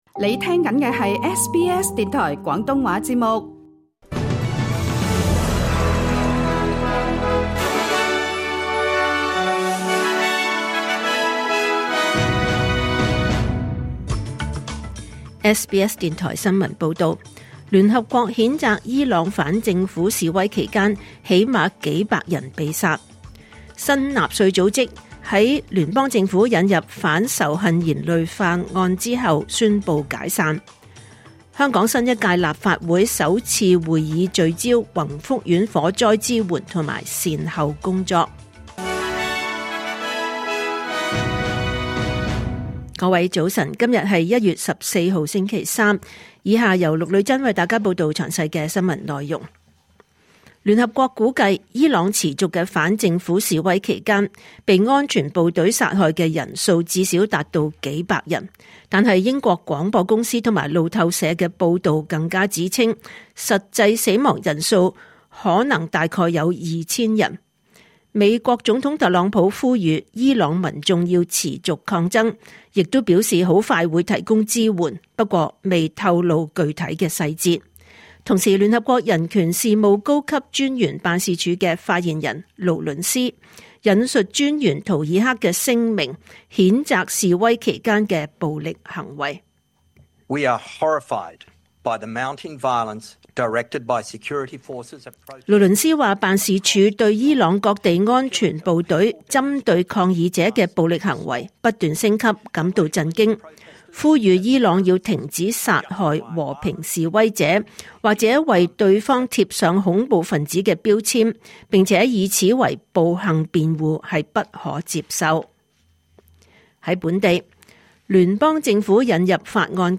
2026年1月14日SBS廣東話節目九點半新聞報道。